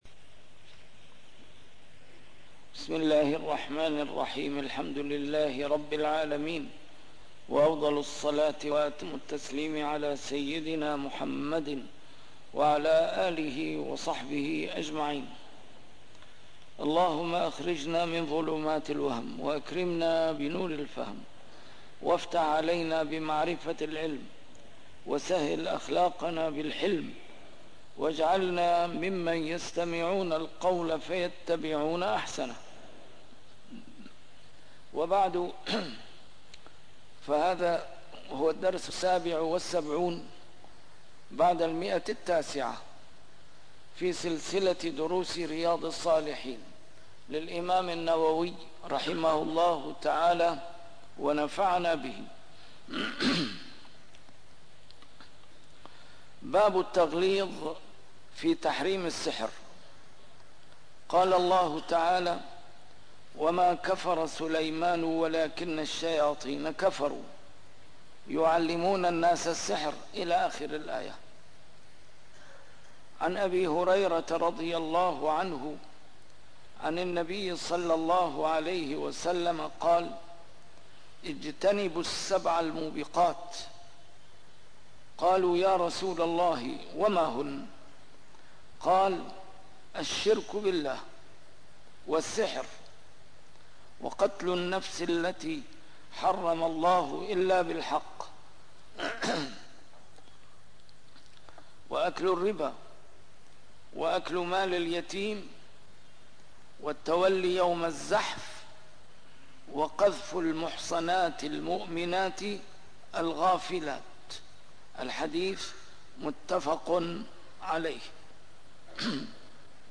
A MARTYR SCHOLAR: IMAM MUHAMMAD SAEED RAMADAN AL-BOUTI - الدروس العلمية - شرح كتاب رياض الصالحين - 977- شرح رياض الصالحين; التغليظ في تحريم السحر